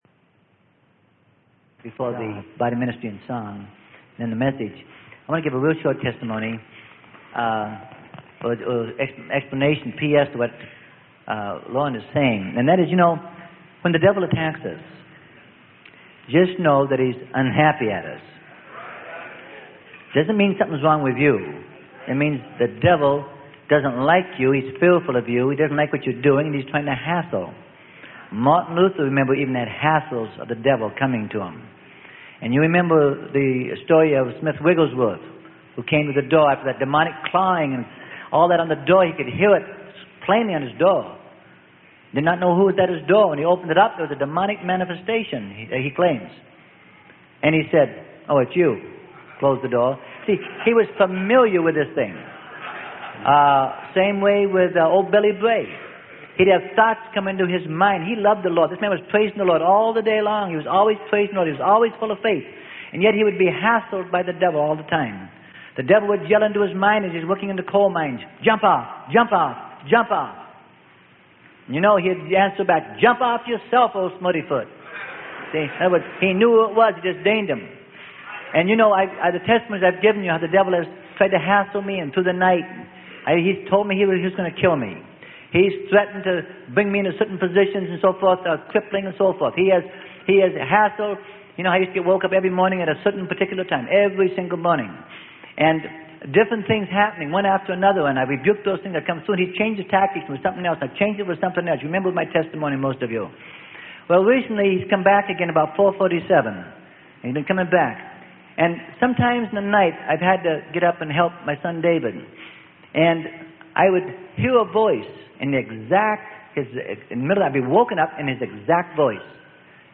Sermon: Fearfully and Wonderfully Made - Freely Given Online Library